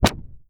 ZAP_Subtle_04_mono.wav